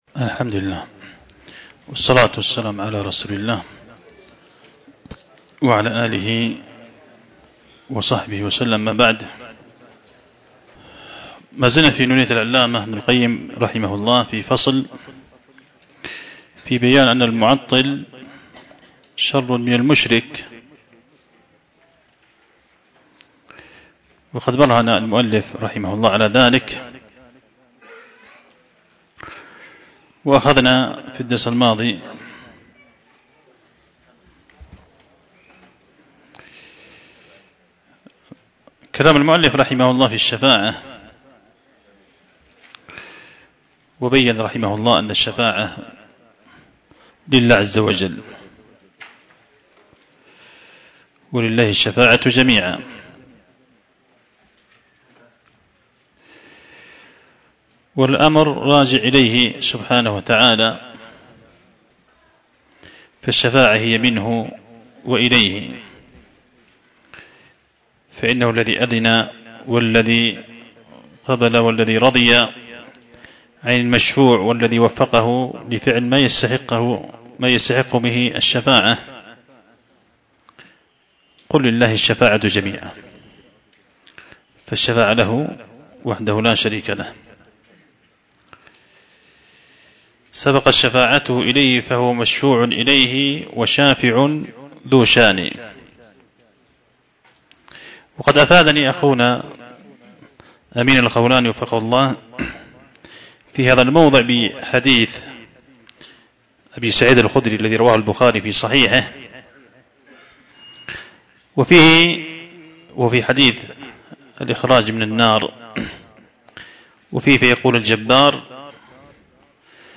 شرح القصيدة النونية لابن القيم